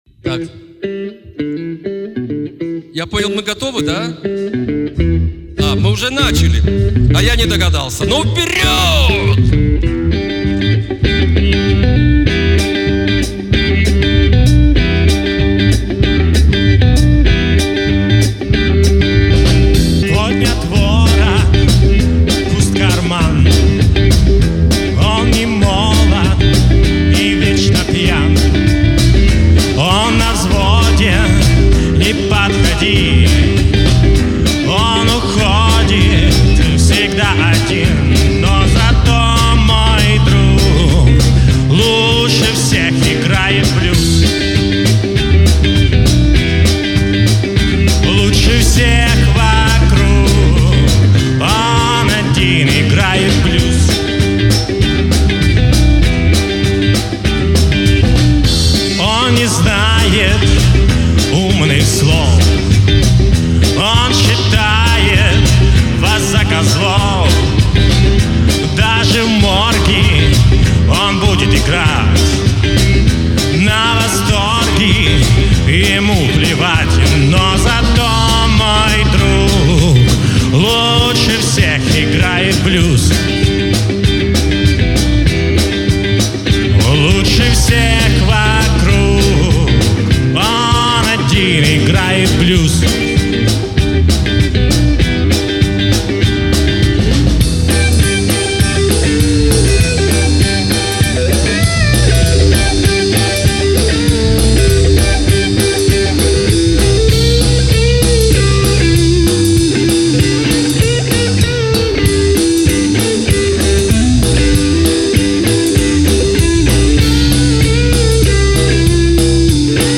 Потому не судите строго-это не студийные вылизанные записи-это как в жизни- по всякому.
Молодые ребята из Сватово.Даже не заметно что их всего трое.